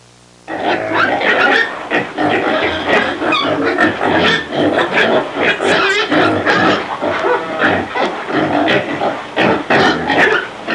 Big Pig Sound Effect
Download a high-quality big pig sound effect.
big-pig.mp3